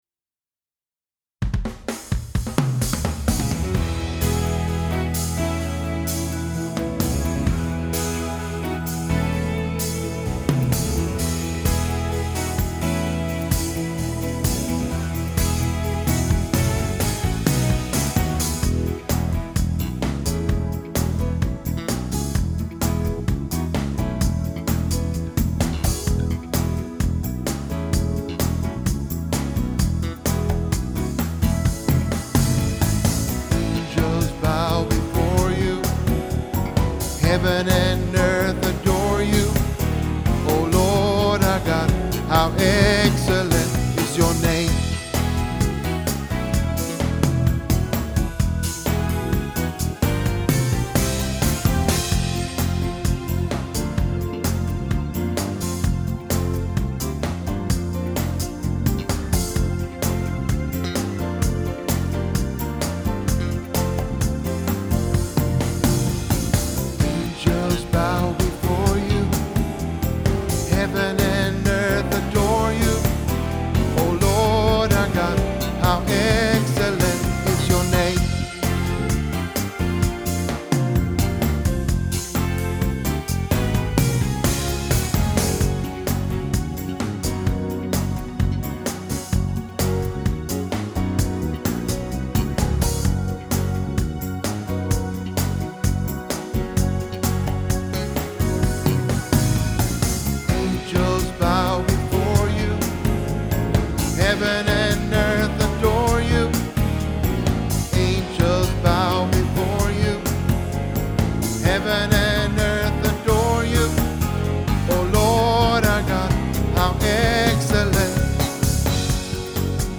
Gospeldagar i Kisa & Eksjö 14-15 maj -22
Lyssna mycket på din stämma och använd endast noterna som extra hjälpmedel.
Excellent_is_Your_name-Bas.mp3